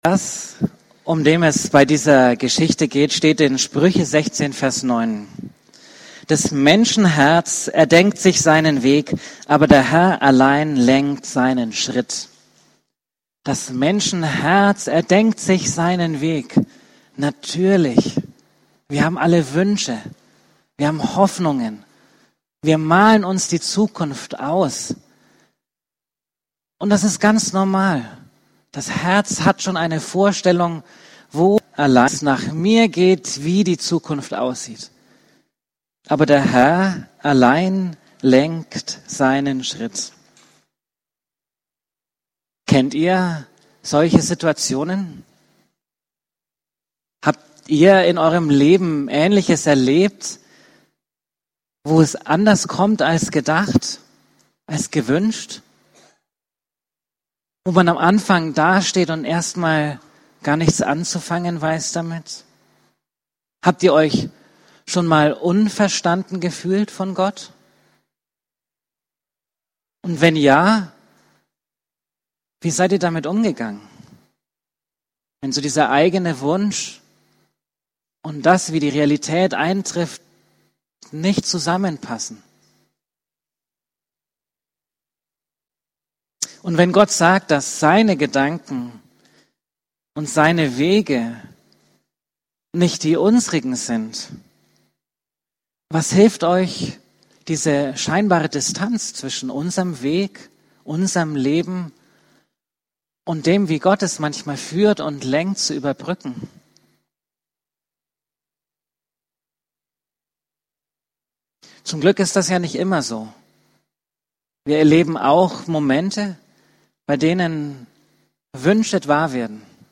Abendmahl 24.10.20
Predigten